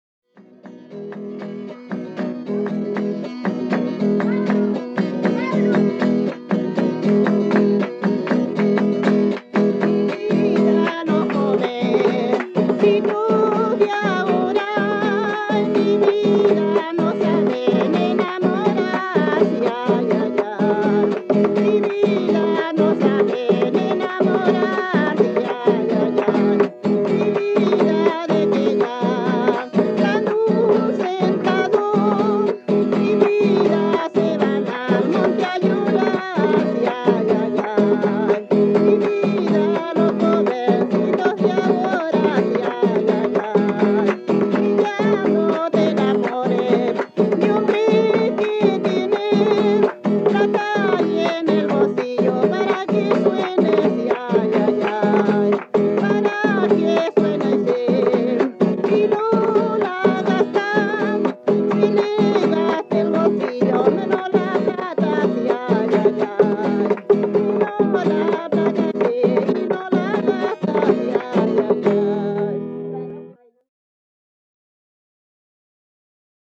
quien se acompaña de una guitarra con afinación por España.
Música tradicional
Folklore
Cueca